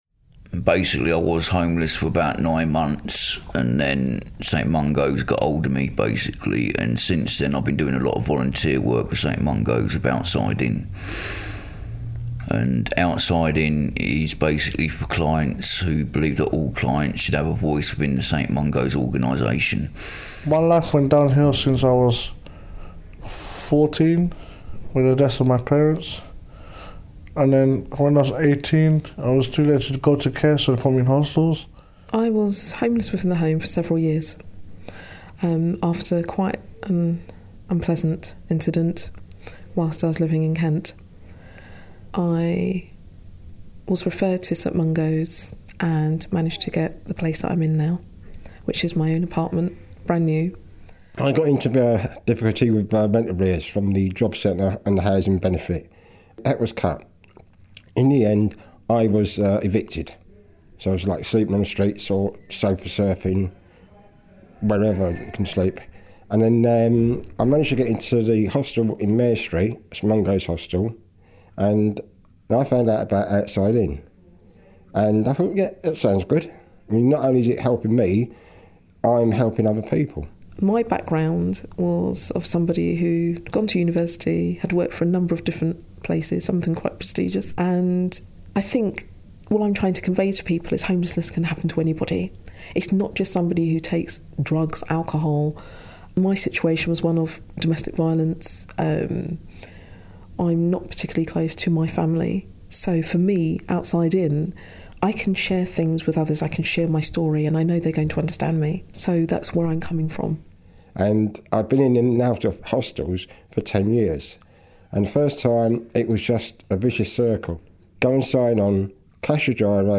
Outside In members talk about their experiences, hopes and aspirations.